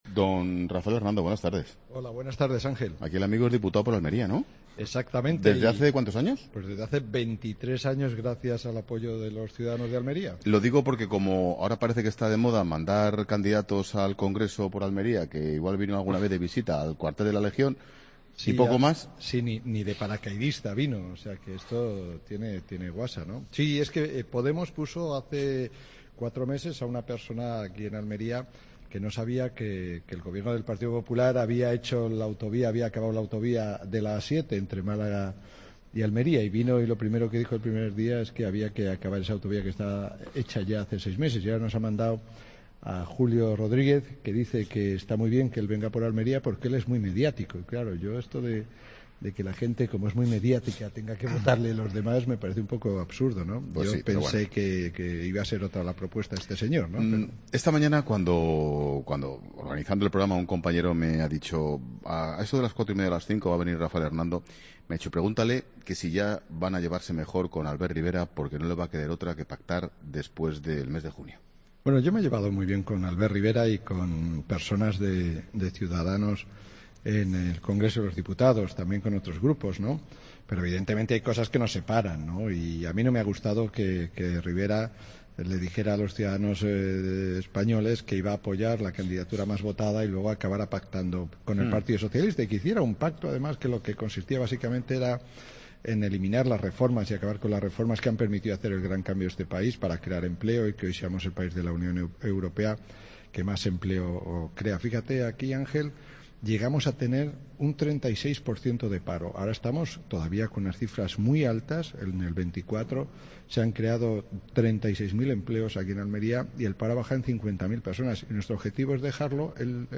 Rafael Hernando, diputado del PP por Almería, en 'La Tarde' desde Roquetas de Mar